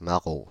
Prononciation
Synonymes salaud fripon Prononciation France (Île-de-France): IPA: /ma.ʁo/ Le mot recherché trouvé avec ces langues de source: français Les traductions n’ont pas été trouvées pour la langue de destination choisie.